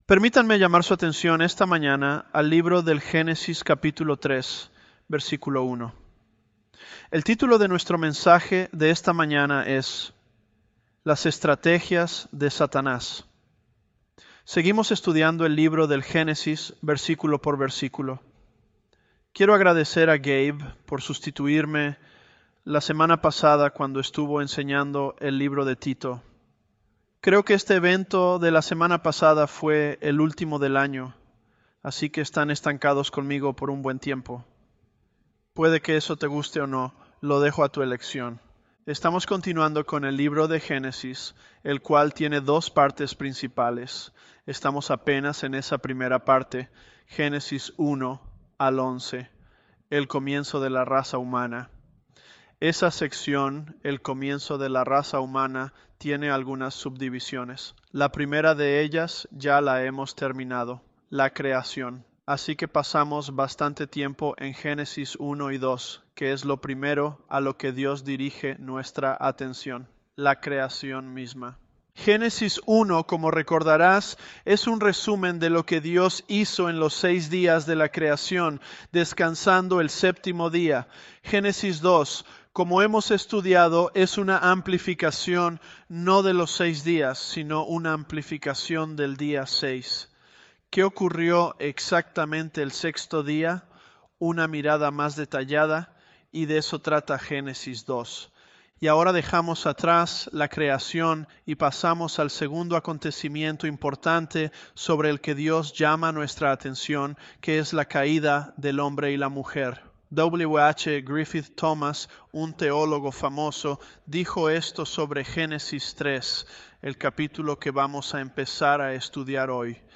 ElevenLabs_Genesis-Spanish011b.mp3